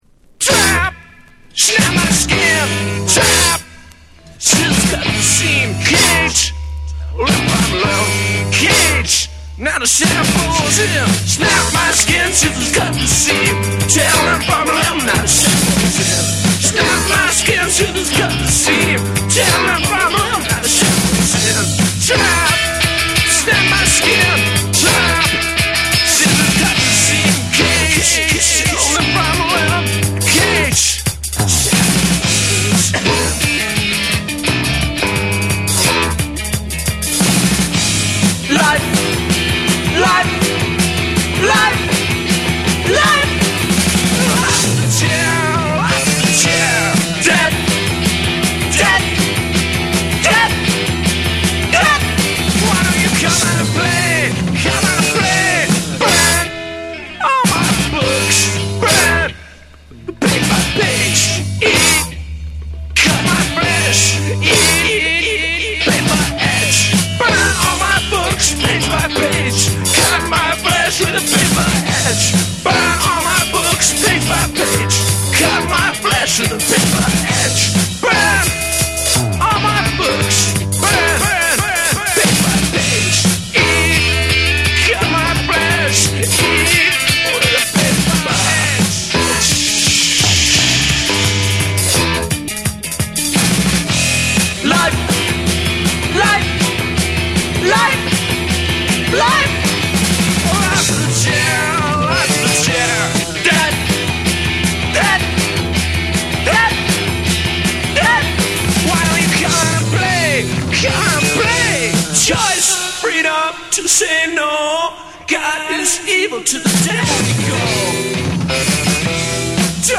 NEW WAVE & ROCK / REGGAE & DUB